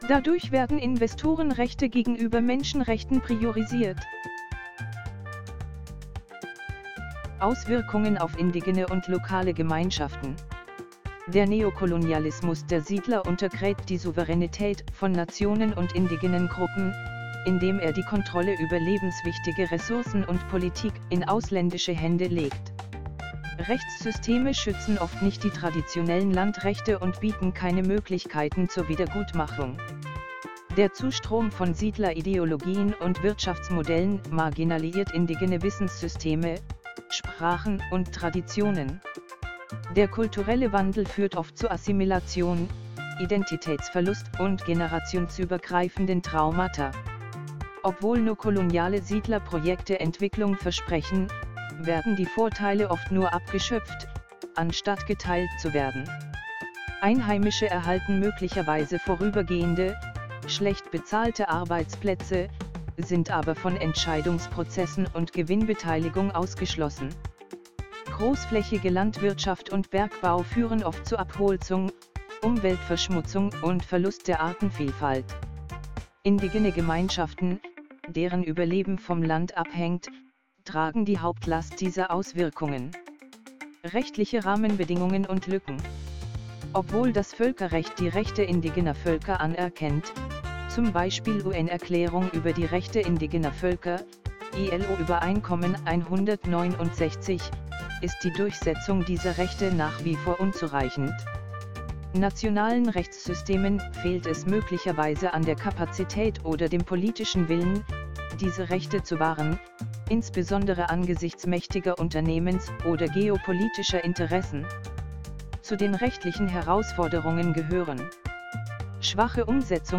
Audiokurs, MP3-Datei, 1 Std.